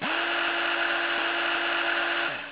gear_up.au